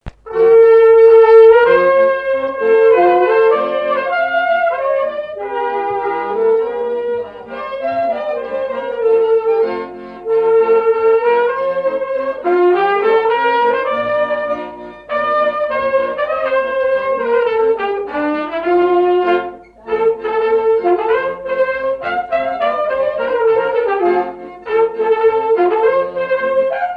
Marche de noce
Mareuil-sur-Lay
Pièce musicale inédite